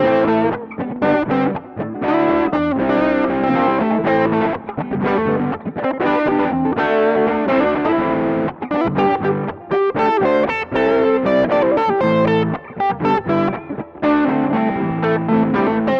硬摇滚电吉他120
标签： 120 bpm Rock Loops Guitar Electric Loops 2.69 MB wav Key : F
声道立体声